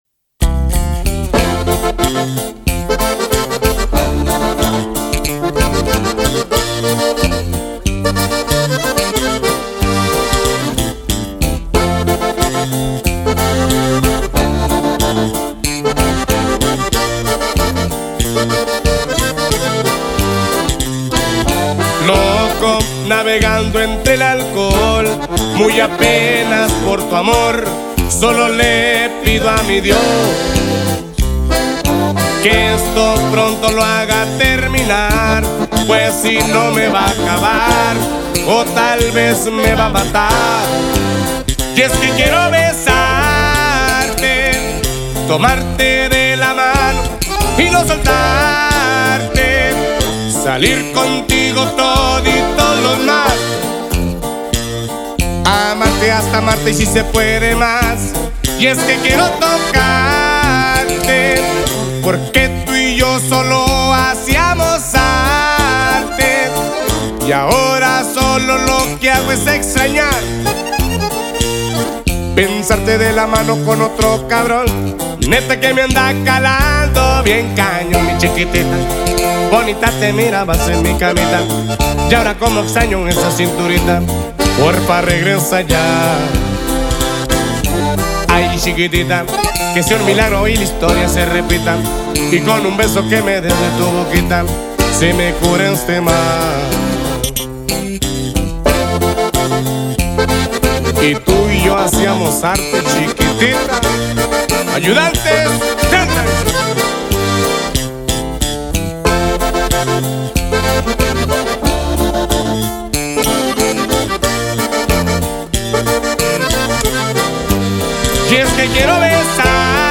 bajo
voz y acordeón
una balada cruda, intensa y llena de sentimiento.